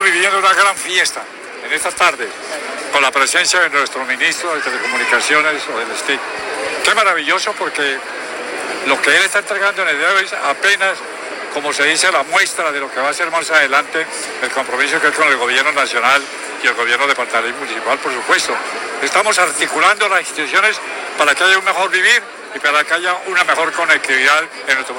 ☝🏻Audio alcalde de Acacías, Carlos Julio Plata Becerra.